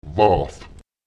Lautsprecher wác [waùT] leicht